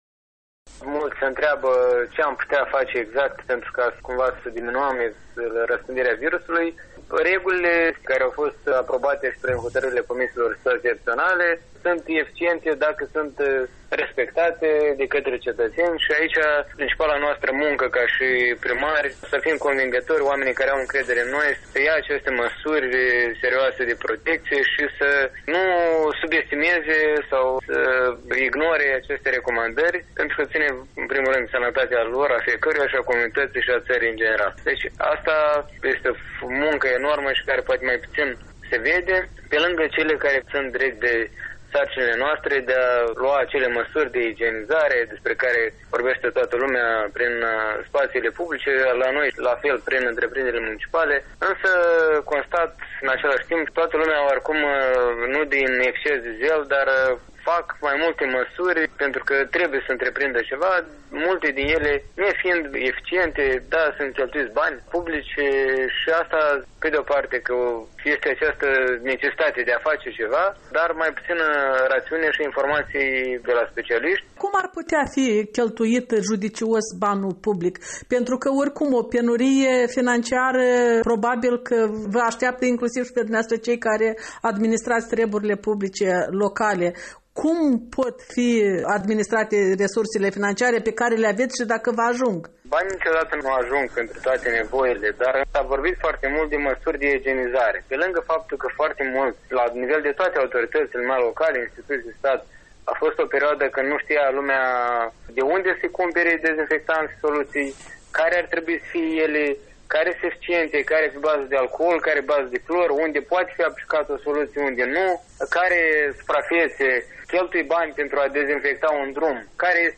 Un interviu cu primarul municipiului Cahul, Nicolae Dandiș.
Interviu cu primarul orașului Cahul, Nicolae Dandiș